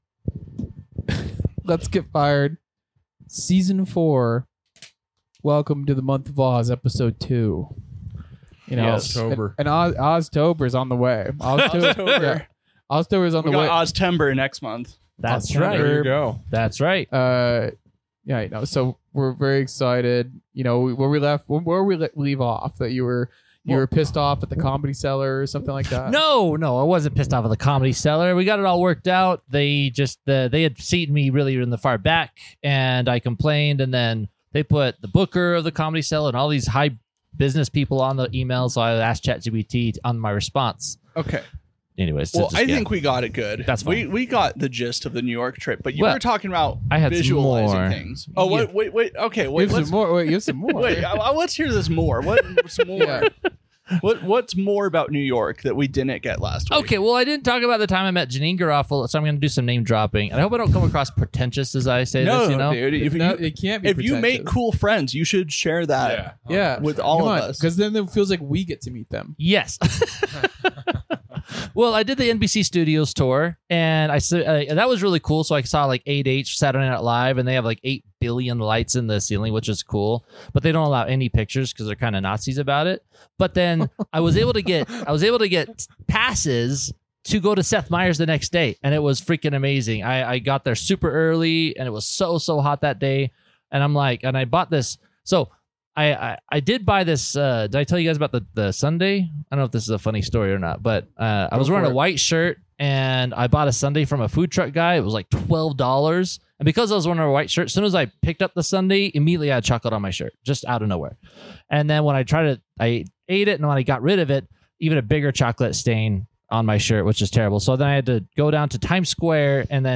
Comedy Interviews